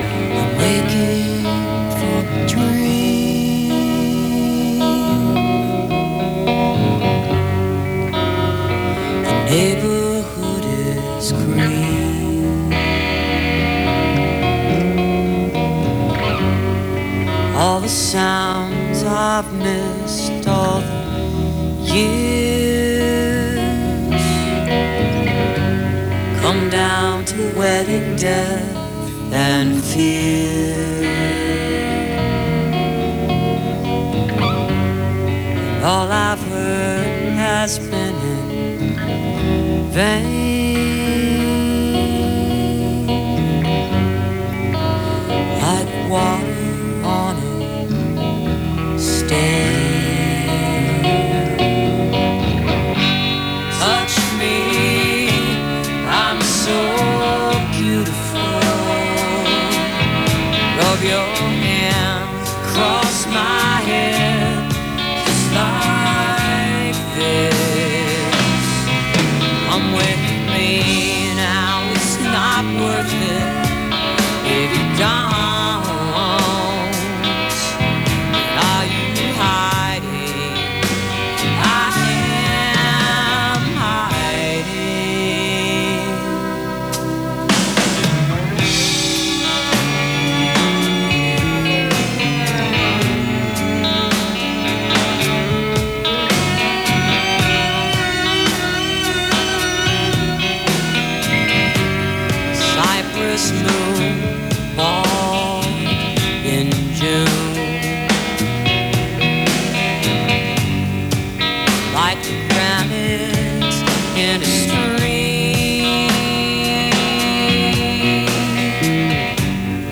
(album version)